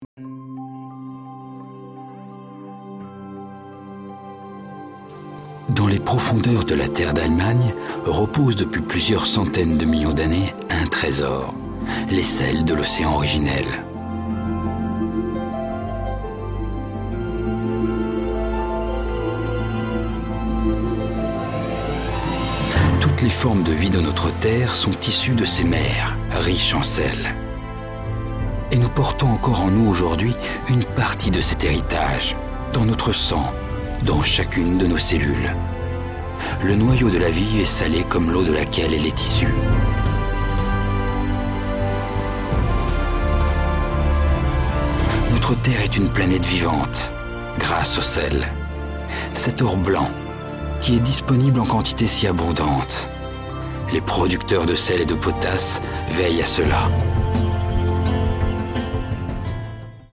Voix off documentaire